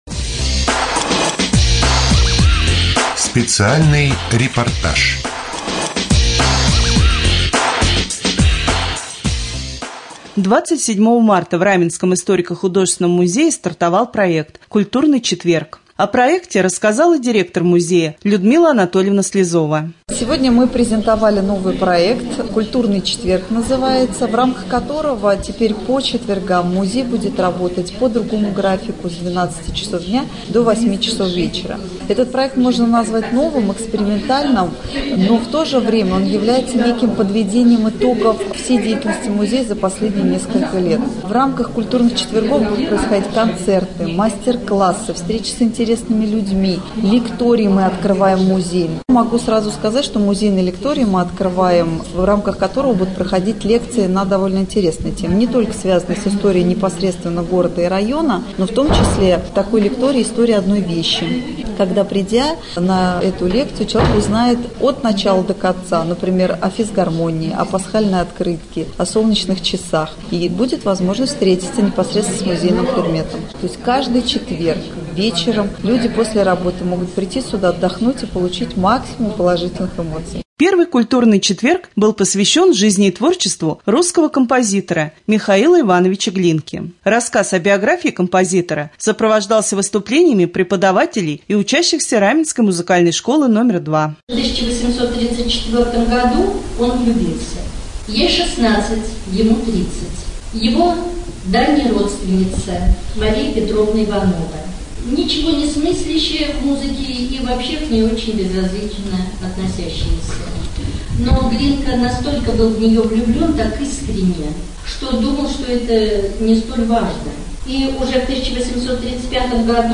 01.04.2014г. в эфире раменского радио - РамМедиа - Раменский муниципальный округ - Раменское
2. Рубрика «Наш репортаж» В Раменском историко-художественном музее стартовал проект «Культурный четверг».